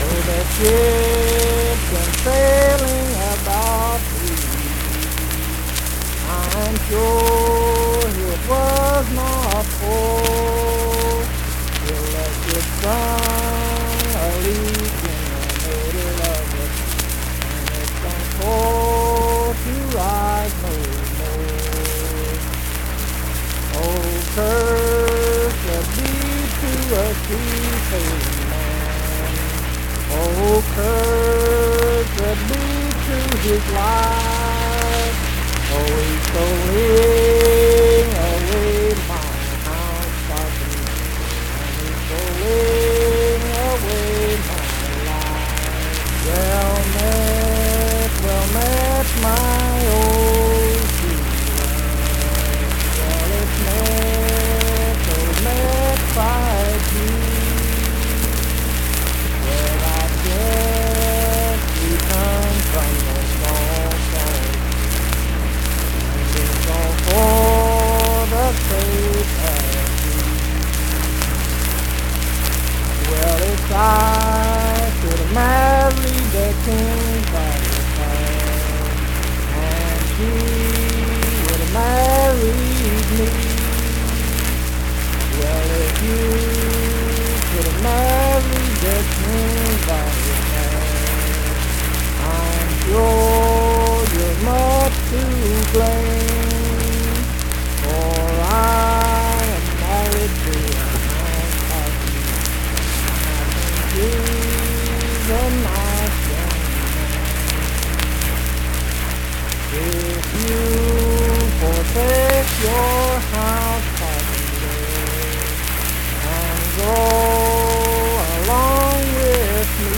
Unaccompanied vocal music
Voice (sung)
Pleasants County (W. Va.), Saint Marys (W. Va.)